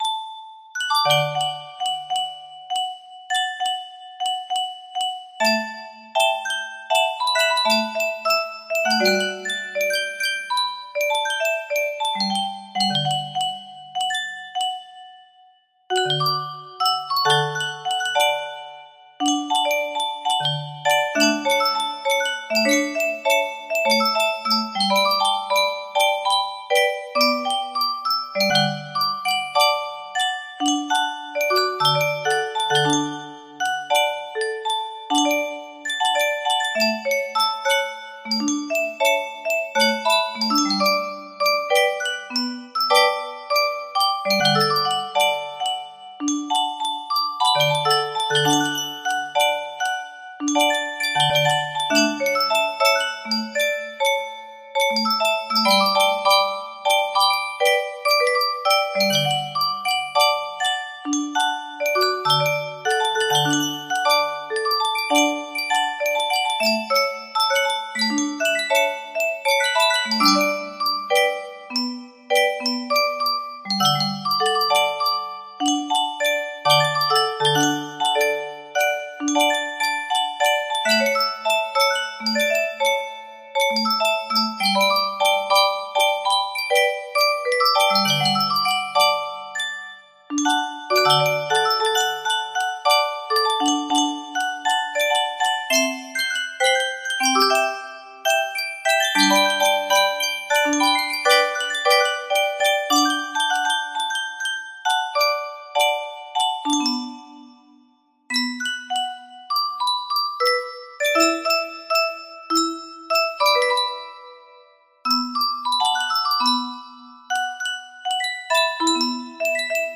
Full range 60
(My Overzealous Musicbox Arrangement)